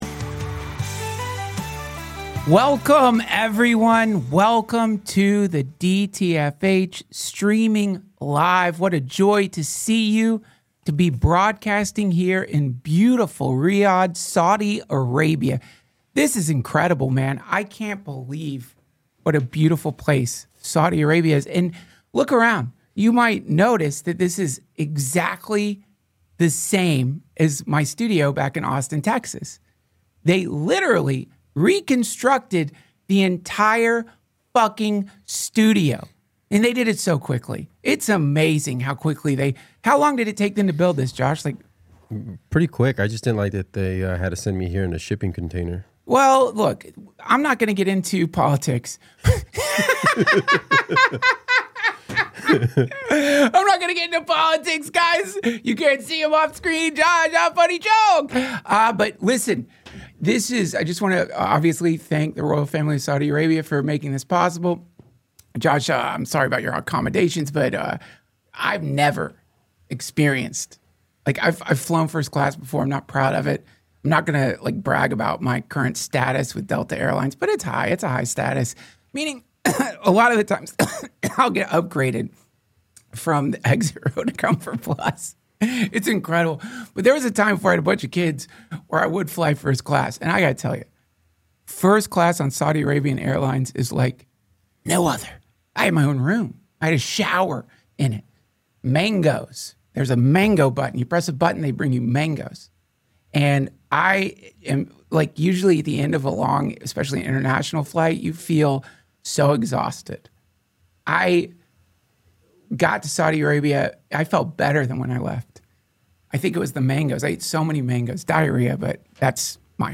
Live from the Riyadh Comedy Festival, from the plushest slave-crafted hotel room imaginable (technically bigger than Burr's), it's the DTFH!